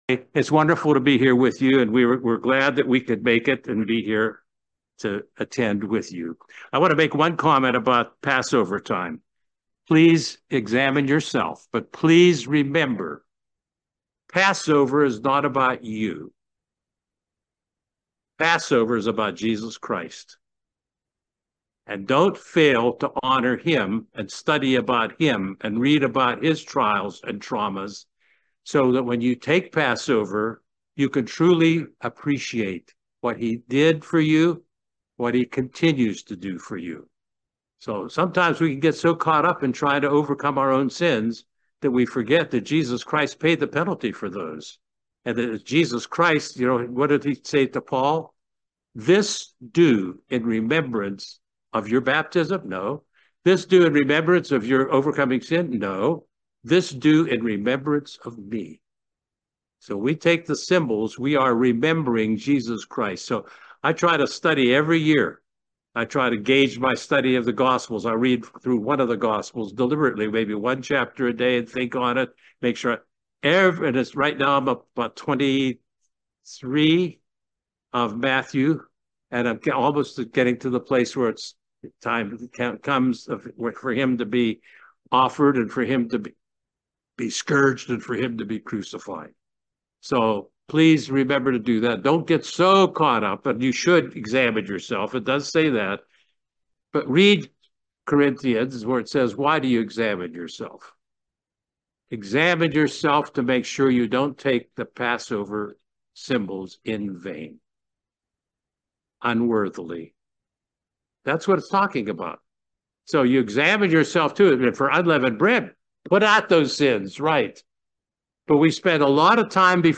Very eyeopening Sermon highlighting the difference between doing things our way and doing things God's way.